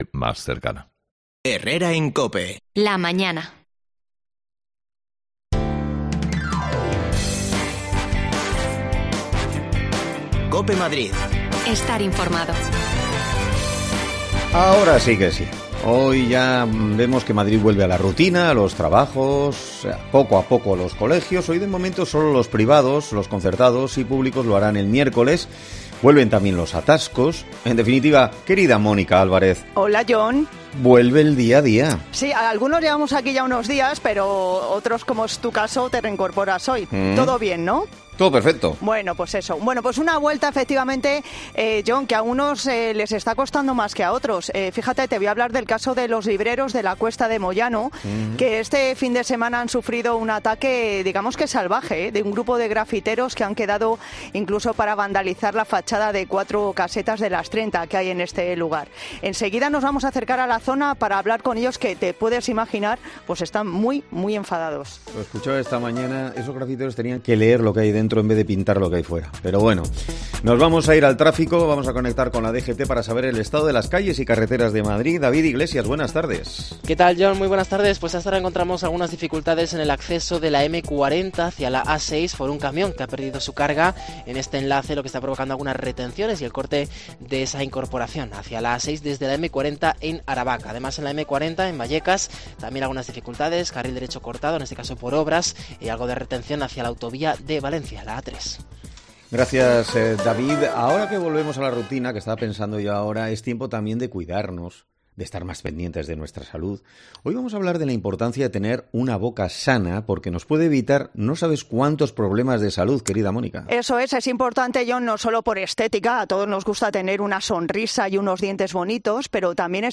Cuatro casetas de la cuesta de Moyano han sido pintadas por grafiteros durante este fin de semana. Nos acercamos allí para hablar con los libreros que están bastante hartos
Las desconexiones locales de Madrid son espacios de 10 minutos de duración que se emiten en COPE , de lunes a viernes.